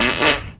文件夹里的屁 " 屁 05
描述：从freesound上下载CC0，切片，重采样到44khZ，16位，单声道，文件中没有大块信息。
Tag: 喜剧 放屁 效果 SFX soundfx 声音